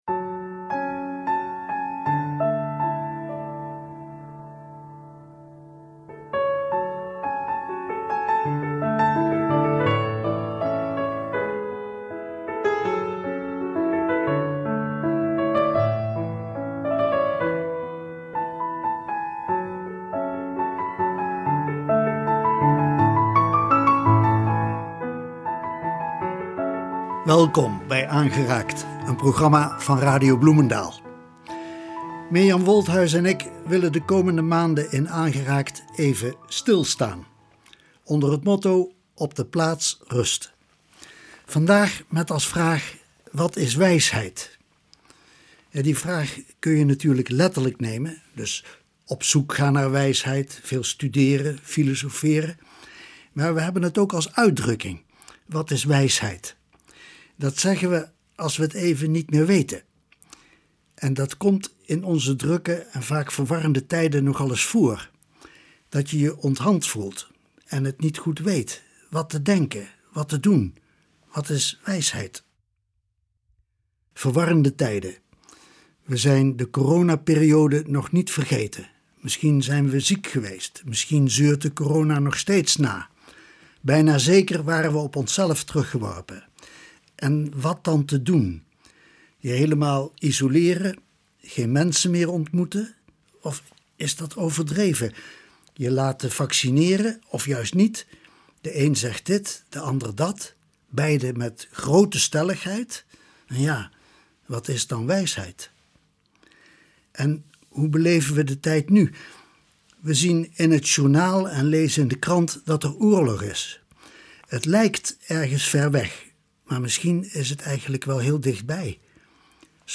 En er is natuurlijk muziek
piano-improvisatie